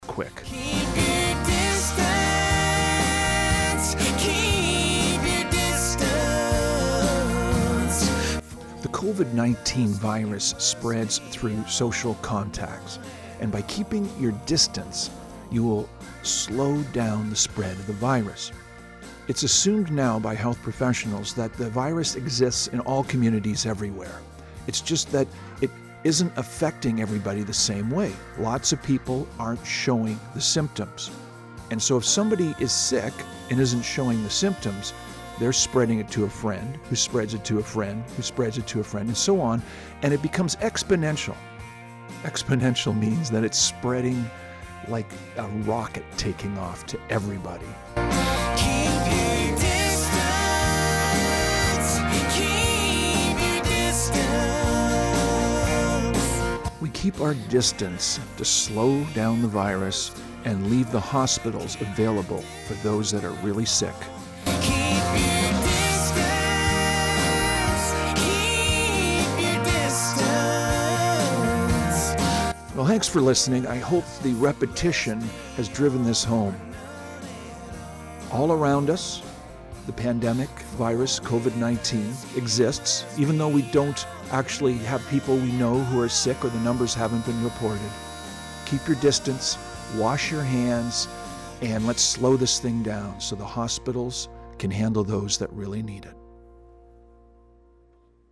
Recording Location: Gabriola Island
Type: PSA